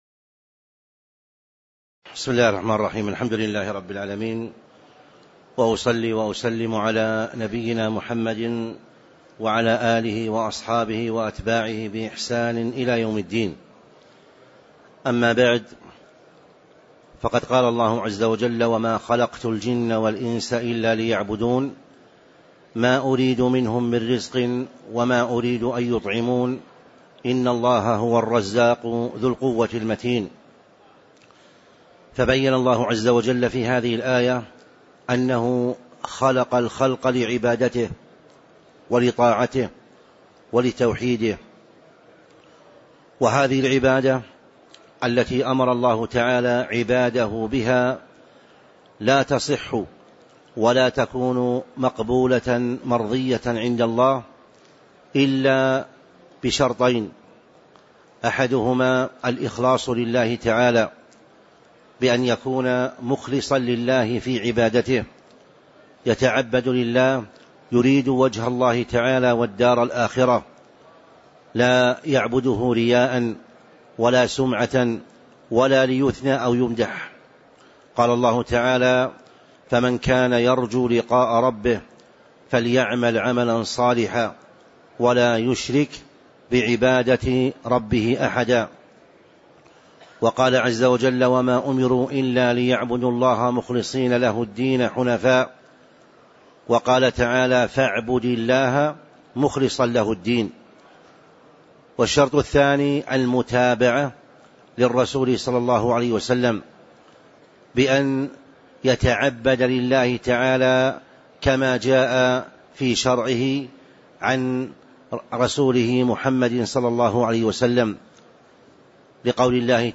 تاريخ النشر ٧ ربيع الثاني ١٤٤٤ هـ المكان: المسجد النبوي الشيخ